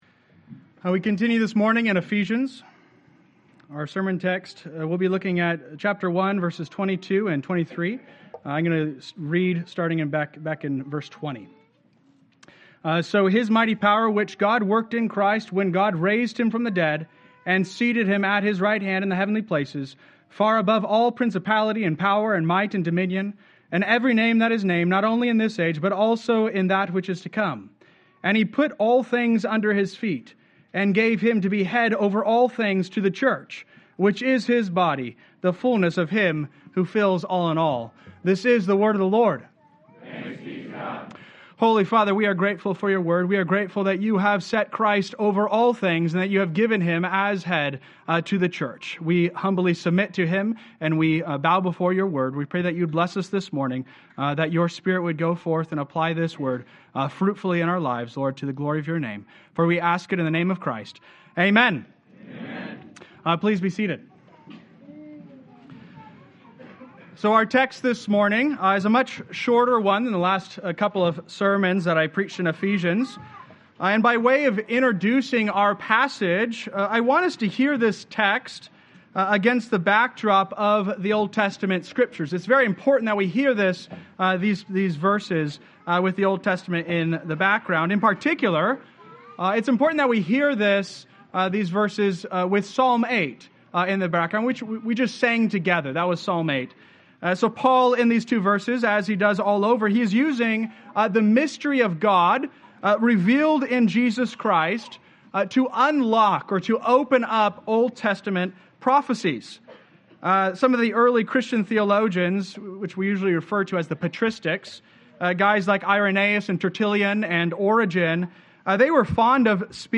Sermon Outline: 03-02-25 Outline Ephesians 1d (Authority)